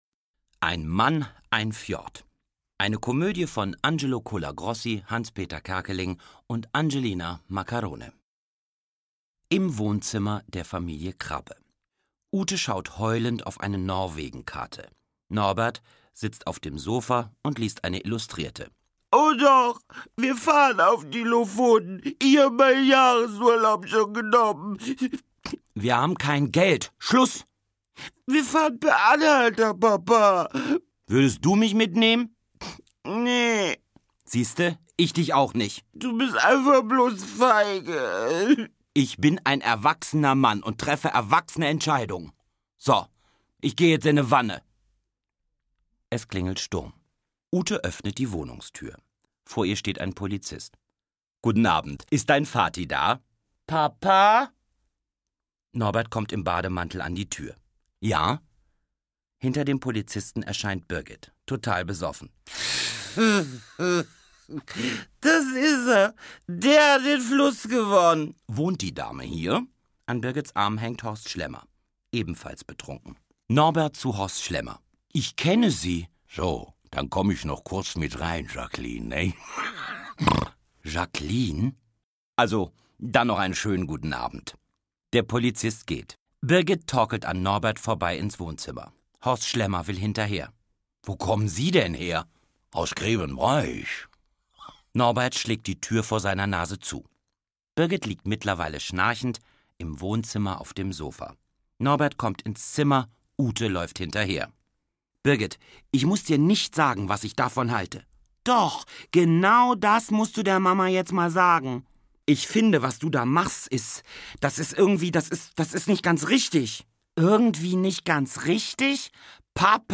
Hape Kerkeling (Sprecher)